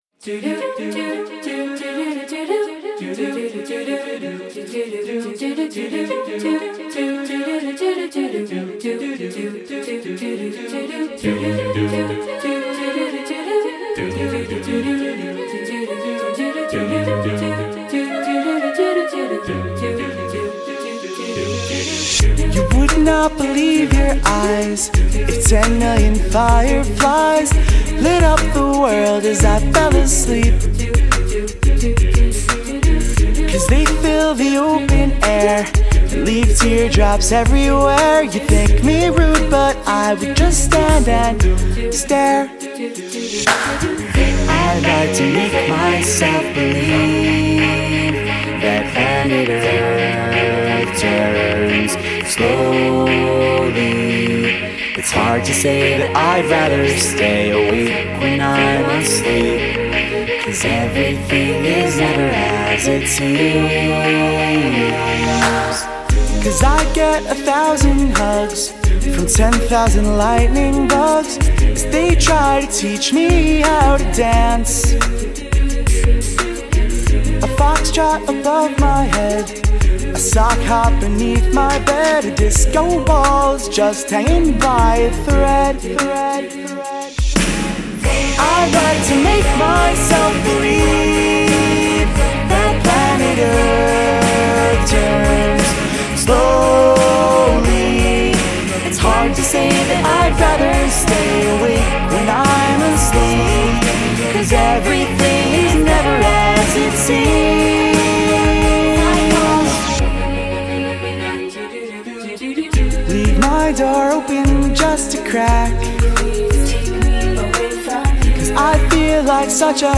Contains solos: Yes